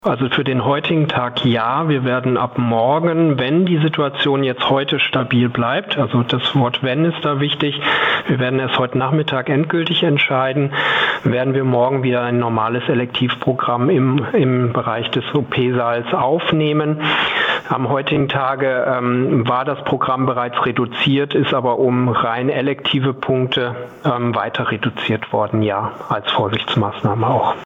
Noro-Virus Ausbruch am Leopoldina Krankenhaus. Interview mit - PRIMATON